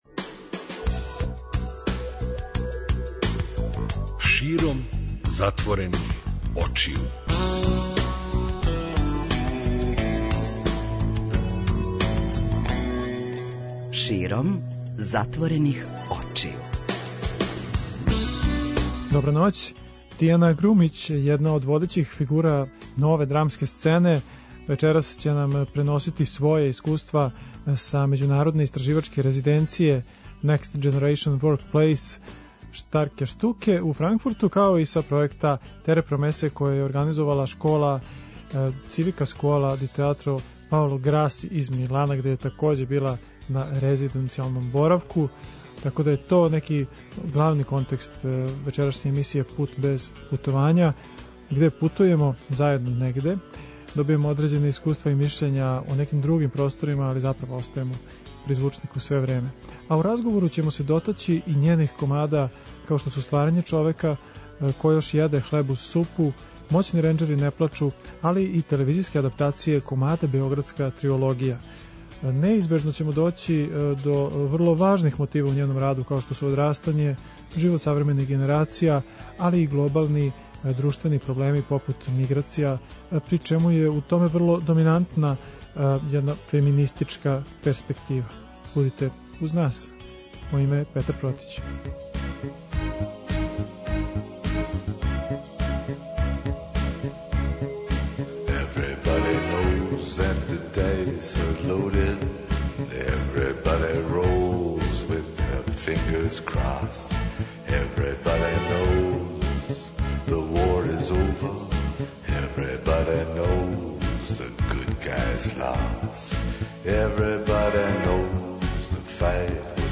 А у разговору ћемо се дотаћи њених комада као што су Стварање човека, Ко још једе хлеб уз супу, Моћни ренџери не плачу, али и телевизијске адаптације комада Београдска триологија. Неизбежно ћемо доћи до врло важних мотива у њеном раду као што су одрастање, живот савремених генерација, али и глобални друштвени проблеми попут миграција, при чему је у томе врло доминантна феминистичка перспектива.